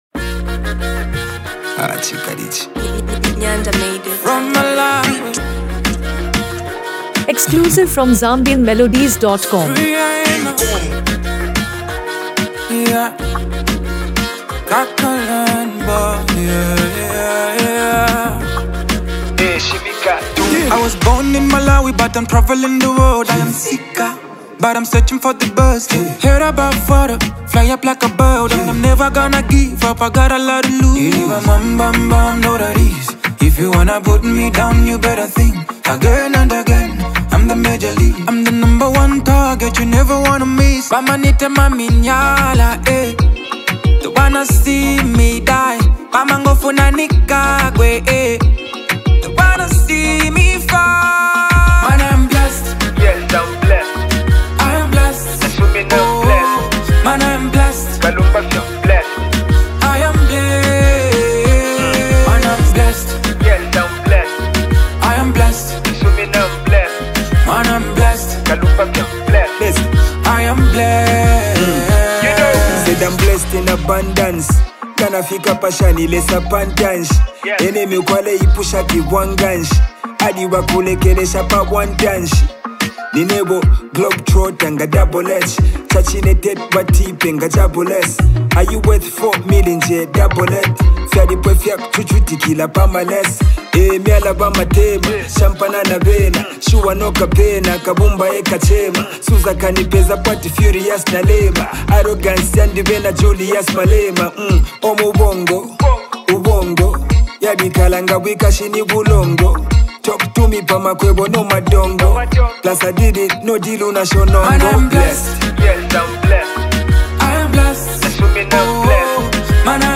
one of Malawi’s fastest-rising Afrobeat and RnB stars
Known for his emotional depth and smooth delivery